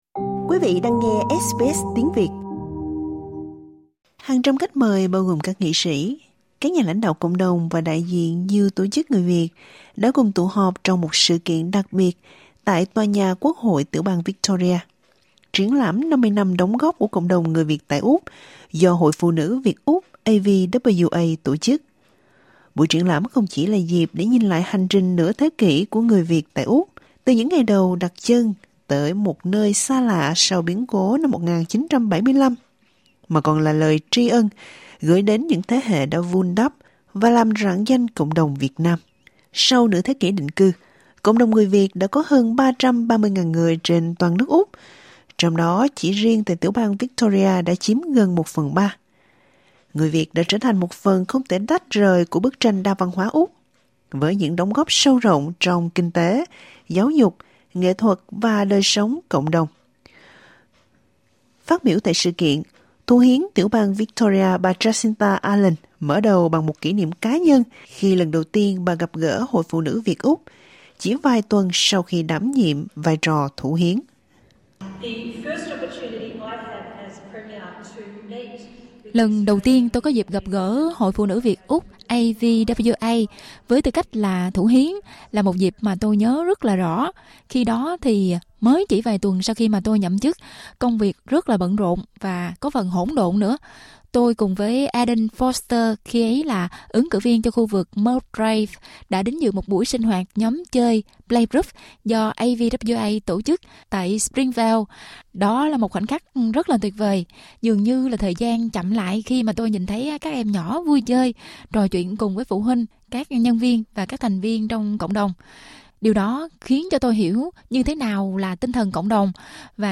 Thrive 2025: Kỷ niệm 50 năm người Việt định cư tại Úc do AVWA tổ chức